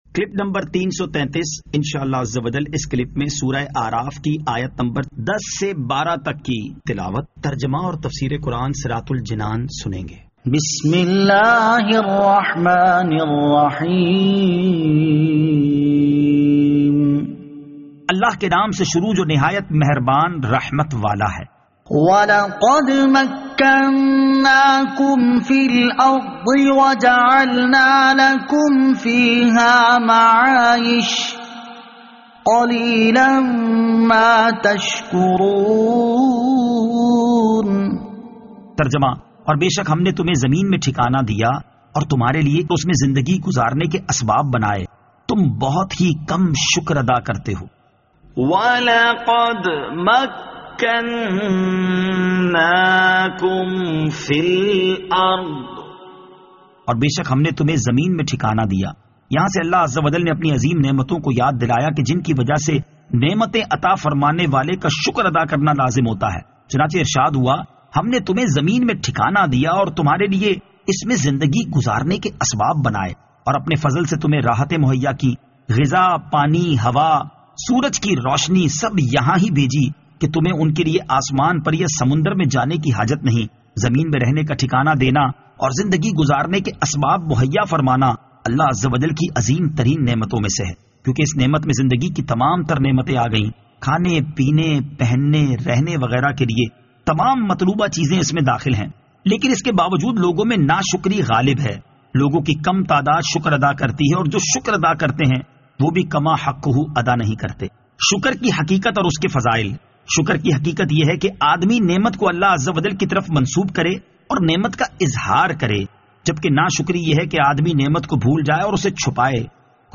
Surah Al-A'raf Ayat 10 To 12 Tilawat , Tarjama , Tafseer